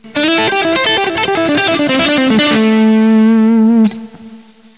אנחנו משתמשים כאן בצלילים הגבוהים בסולם,
בסגנון בלוזי אופייני.
ככה זה נשמע מהר